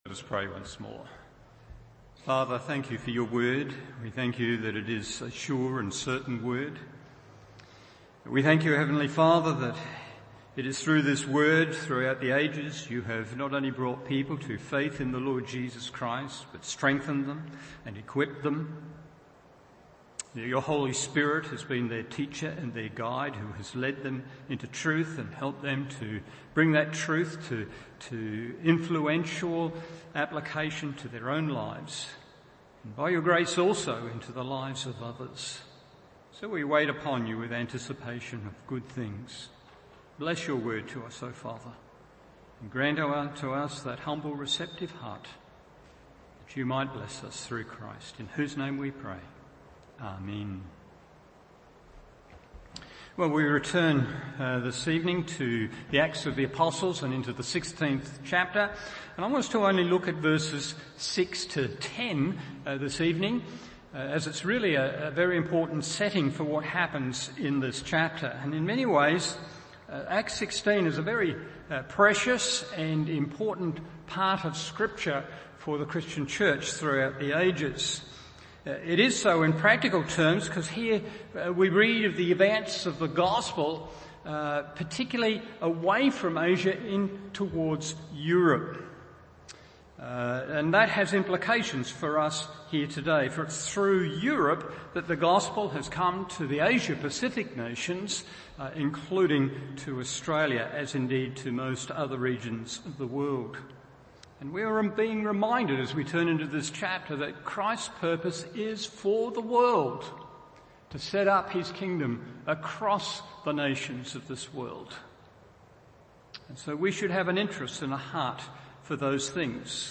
Evening Service Acts 16:6-10 1. Enthusiastic Operatives 2. Unexpected Obstacles 3.